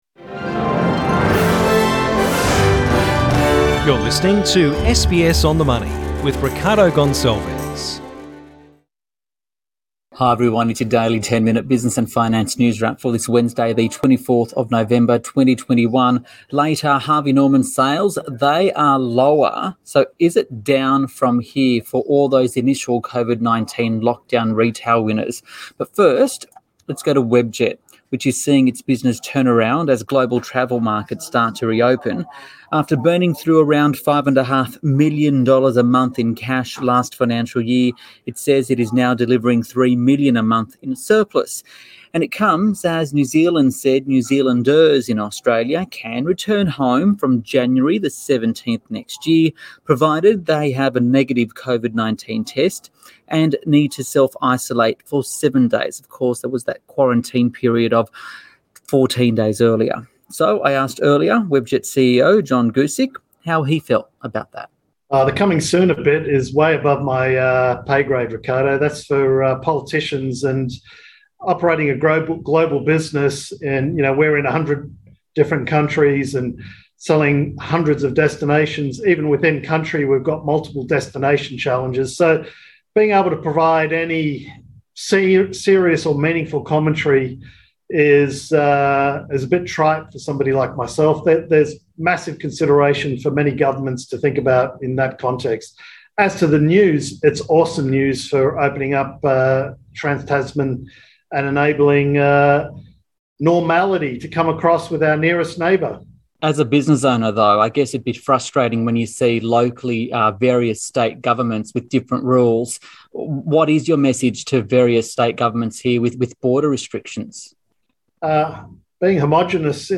SBS On the Money: CEO Interview